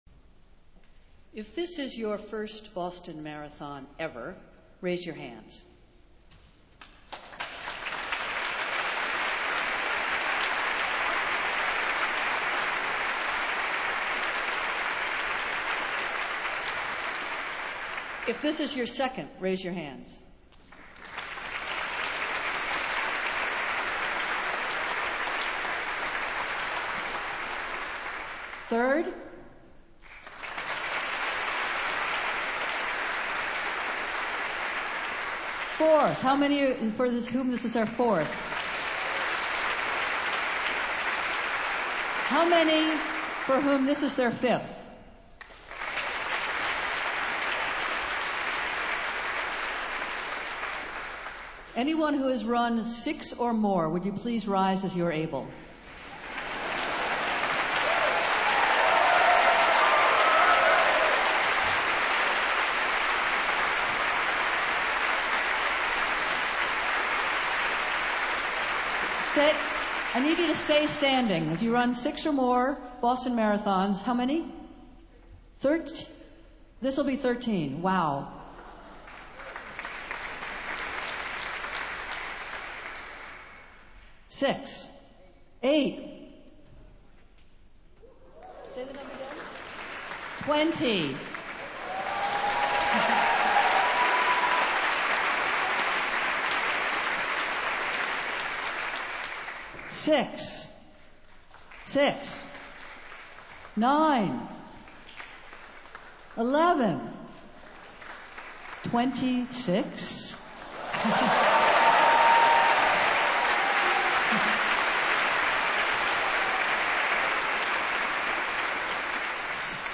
Festival Worship - Blessing of the Athletes